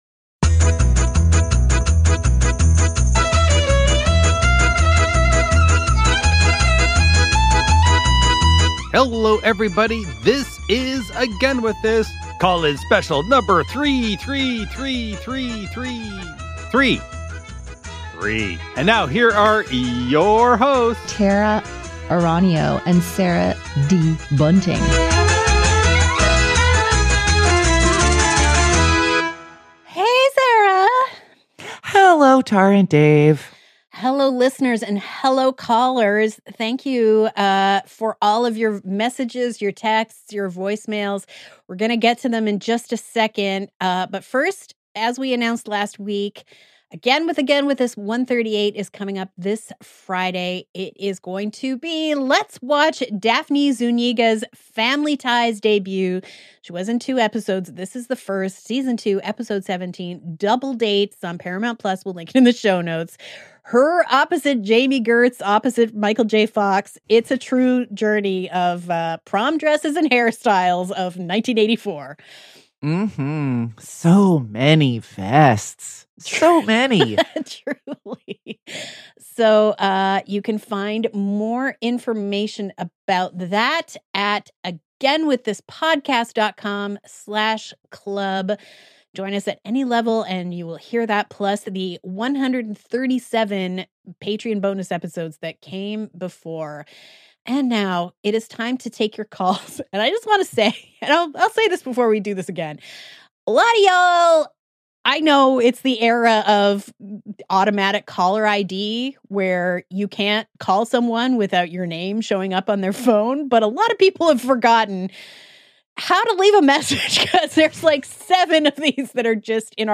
You and your voicemails are so precious to us!
We knew you had more on your mind, and you proved us right with an all-new round of calls.